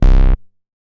MoogResoG 005.WAV